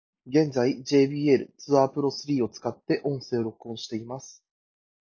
jbl-tour-pro3-mic.m4a